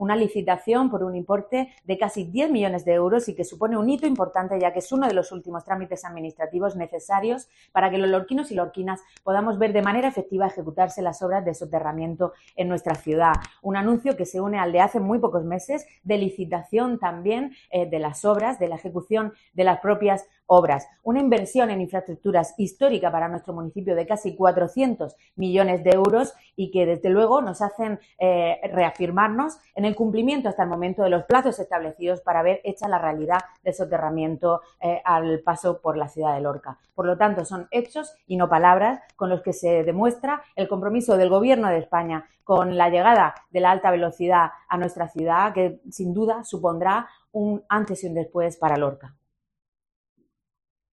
Isabel Casalduero, concejal del PSOE en Lorca